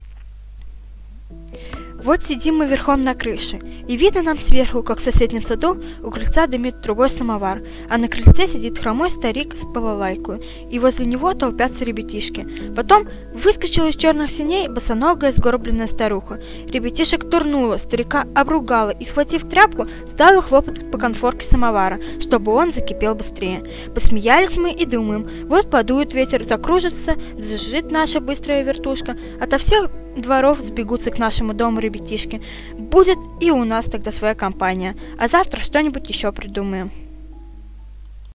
Январский подкаст студии звукозаписи Наумовской библиотеки приурочен к 120-летию со дня рождения детского писателя Аркадия Петровича Гайдара.
А потом вместе записали аудиотреки отрывков из произведений «Чук и Гек», «Голубая чашка», «Горячий камень» и, конечно, «Тимур и его команда».
Rasskaz-Golubaya-chashka-Arkadij-Gajdar.mp3